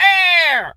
bird_vulture_hurt_04.wav